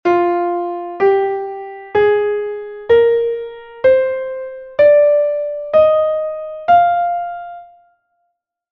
fa_dorica.mp3